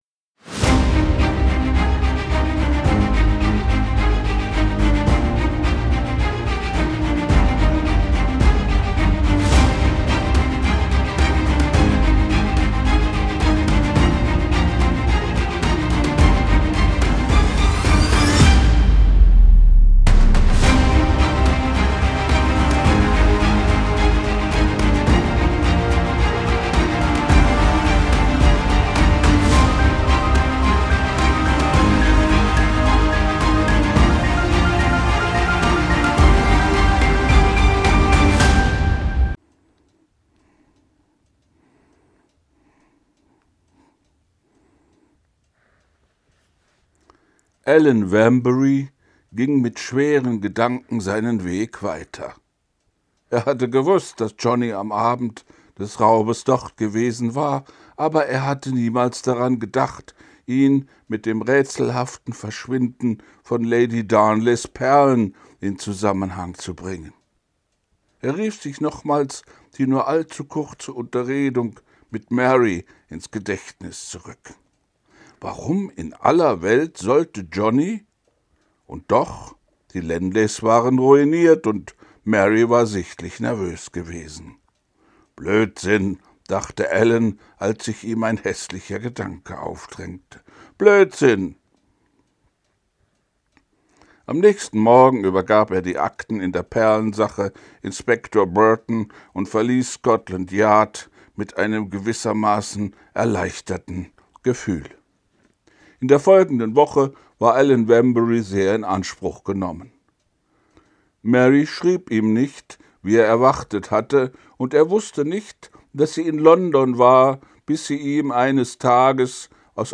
ich lese vor wallace hexer 6